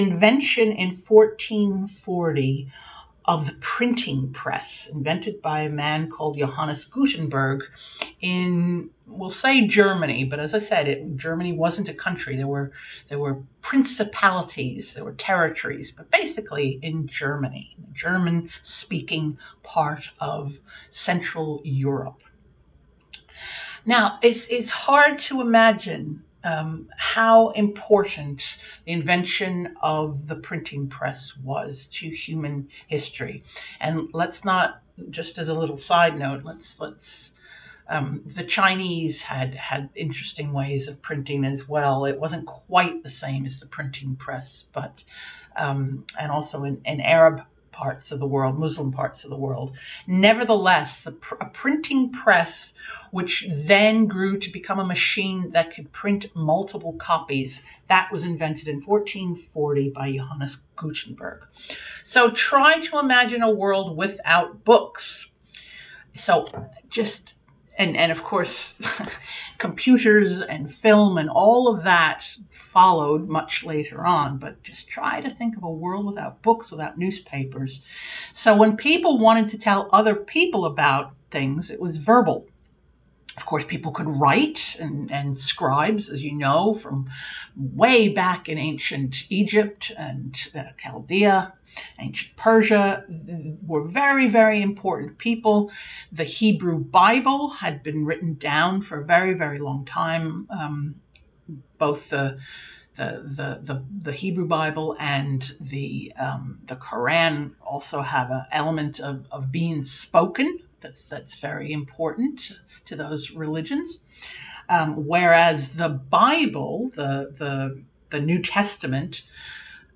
Here are two short excerpts of talks on Roman and Medieval history:
Medieval history excerpt
Medieval-talk-preview.mp3